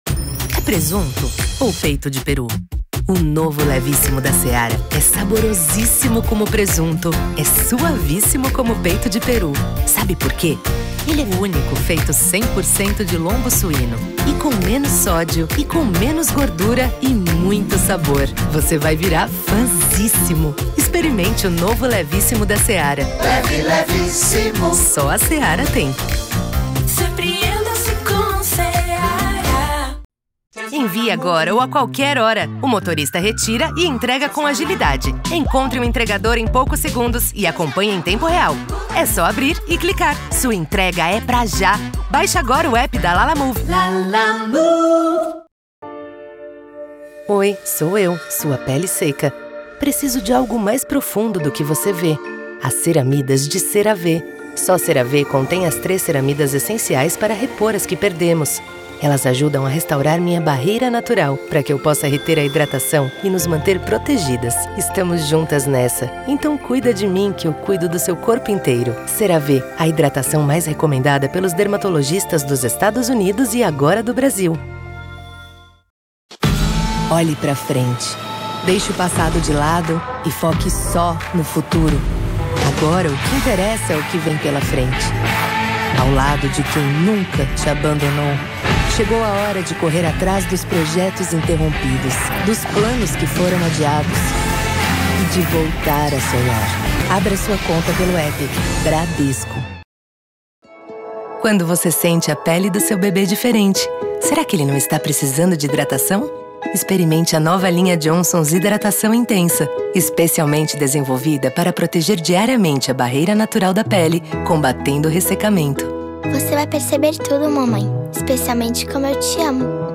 Feminino
PUBLICIDADE NACIONAL
Voz Jovem 02:40